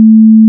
Combat (8): sword, bow, zombie_hit, zombie_death, hurt, shield, explosion, raider
**⚠  NOTE:** Music/SFX are PLACEHOLDERS (simple tones)
zombie_hit.wav